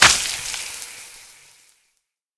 projectile-acid-burn-long-2.ogg